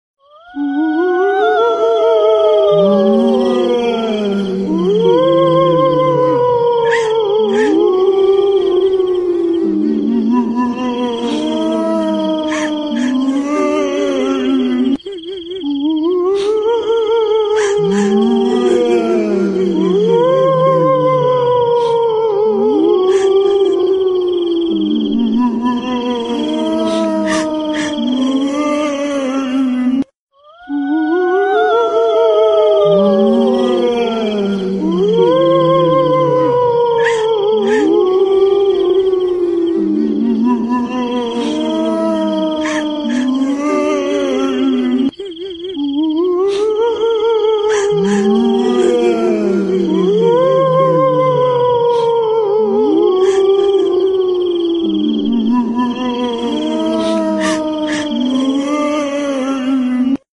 Scary Ghost Catching A Little Sound Effects Free Download